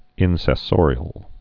(ĭnsĕ-sôrē-əl)